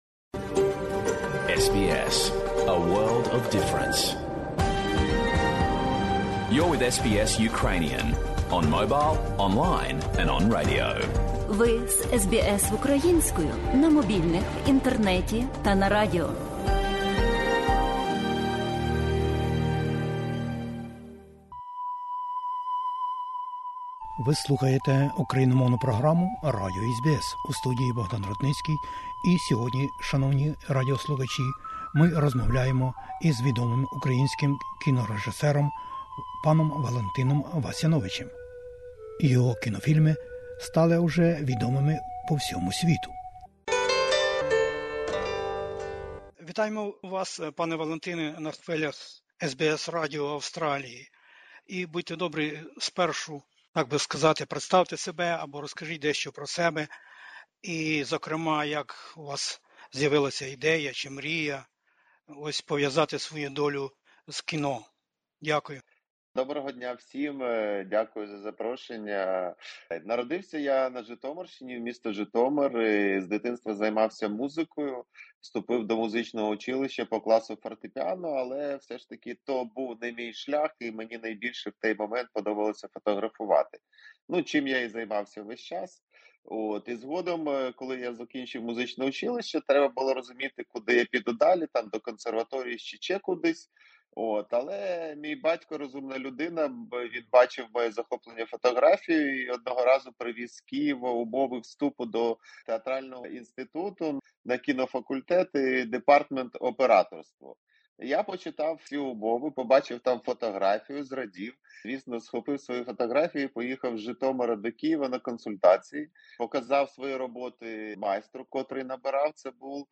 У Мельбурні, у рамцях міжнародного кінофестивалю, 10 і 19 серпня 2022-го року, відбудеться прем'єра українського кінофільму з англійськими субтитрами пана Валентина Васяновича (Valentyn Vasyanovych). З відомим кінорежисером і директором - розмова на хвилях україномовної радіопрограми SBS Ukrainian…